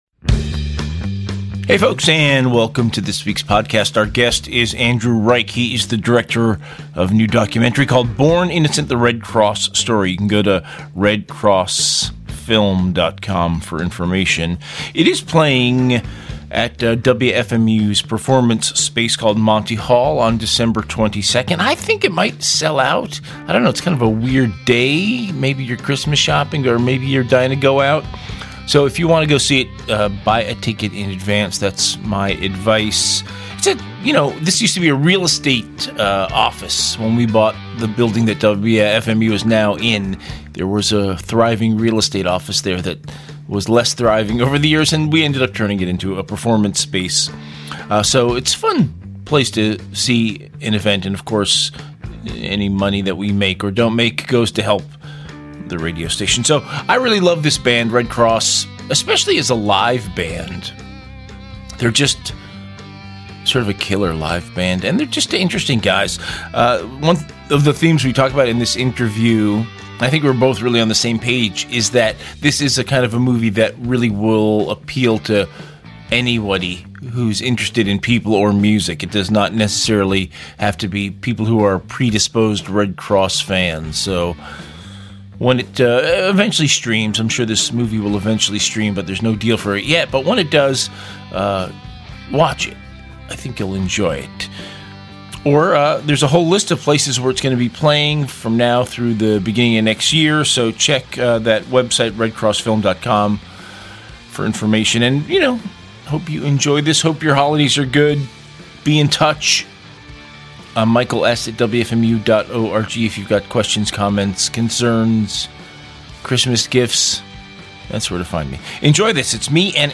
"Interview"https